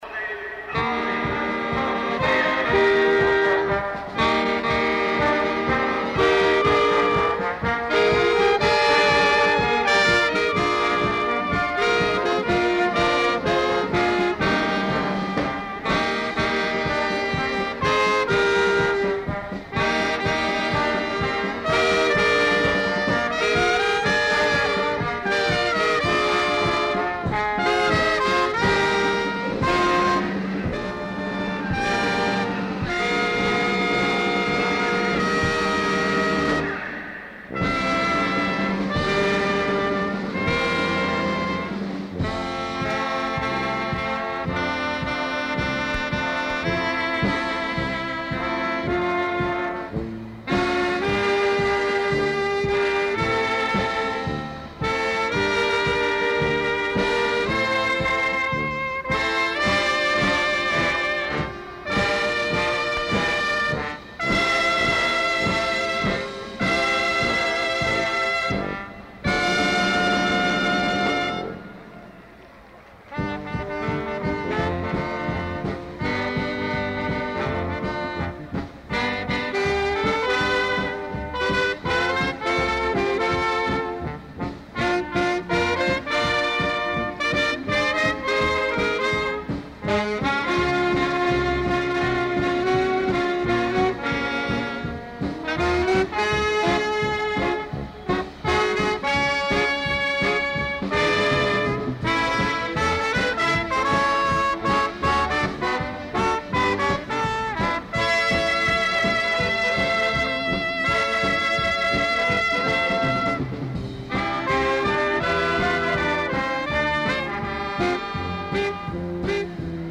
The 18th Annual Aladdin Temple Shrine Circus took place in Columbus, Ohio in April 1968 in the Ohio State Fairgrounds coliseum. The first-rate band of local musicians